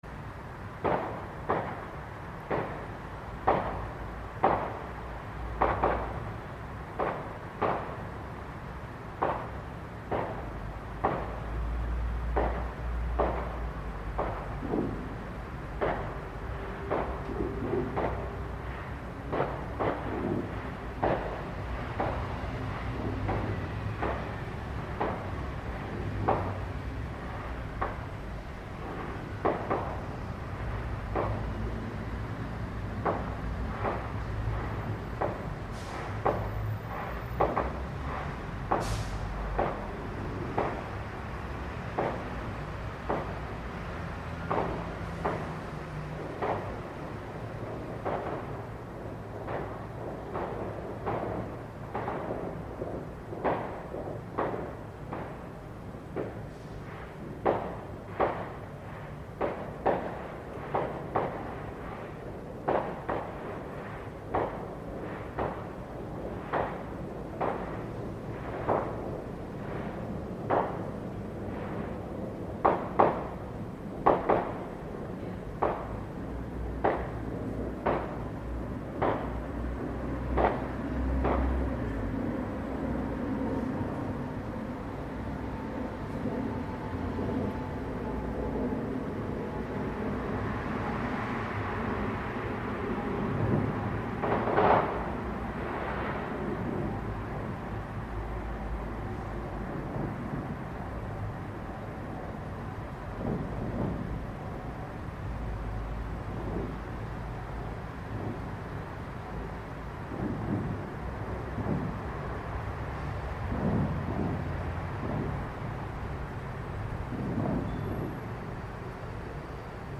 More fireworks from various displays in Richmond on 1 November